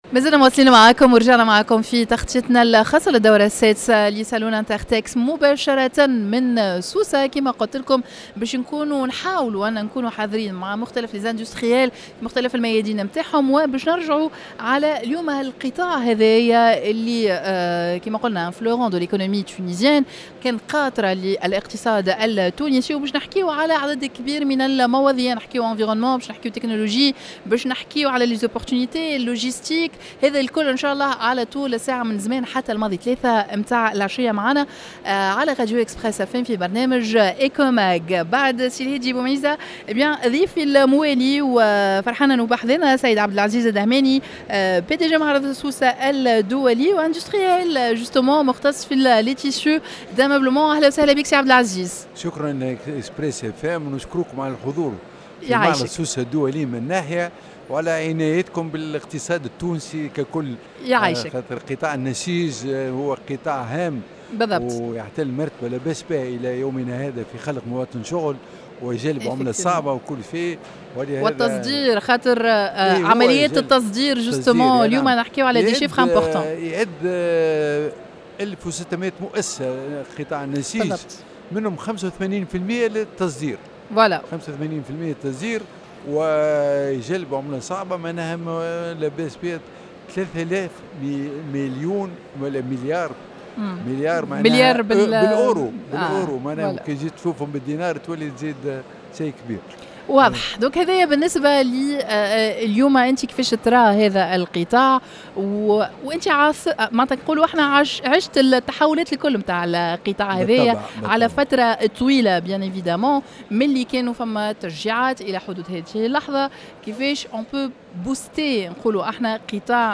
INTERTEX Tunisia dans un plateau spécial en direct de la foire internationale de Sousse.